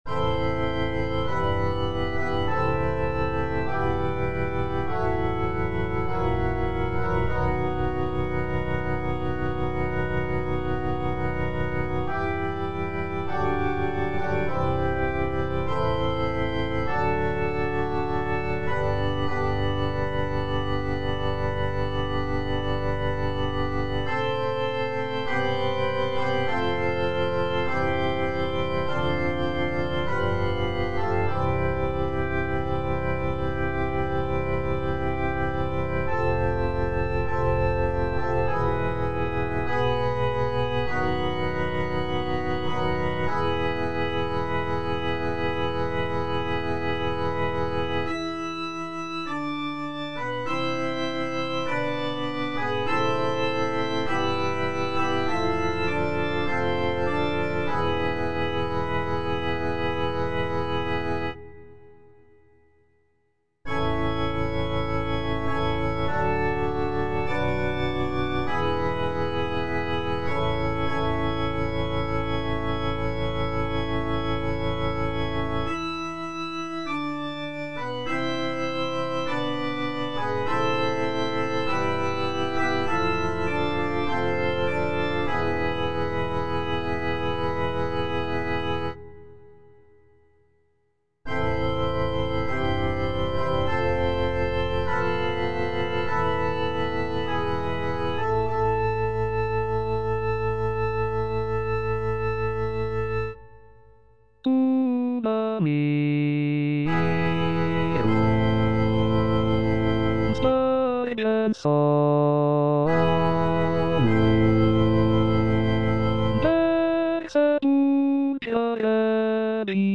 F. VON SUPPÈ - MISSA PRO DEFUNCTIS/REQUIEM Tuba mirum (tenor I) (Emphasised voice and other voices) Ads stop: auto-stop
The piece features lush harmonies, soaring melodies, and powerful choral sections that evoke a sense of mourning and reverence.